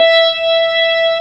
55o-org17-E5.wav